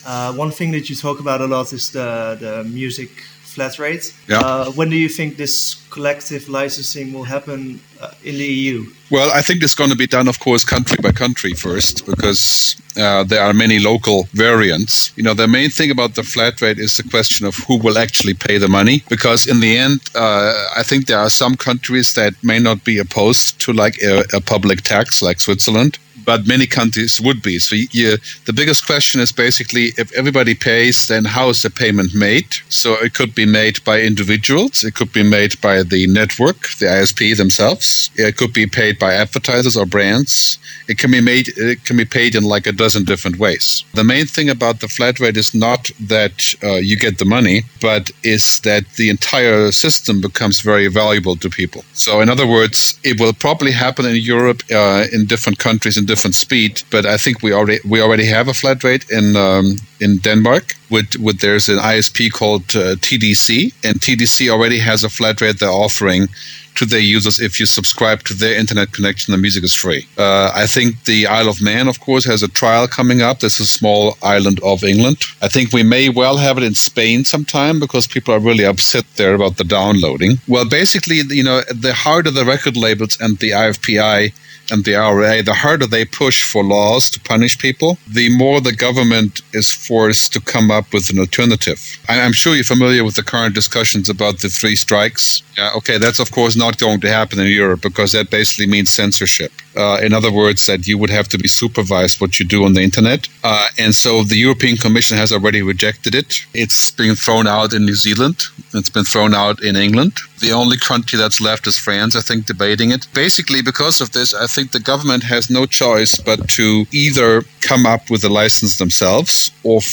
Yesterday I had an interview with Gerd Leonhard, a media futurist, and probably the foremost thinker on the topic of my thesis; The Future of Music Distribution.